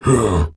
Male_Grunt_Hit_05.wav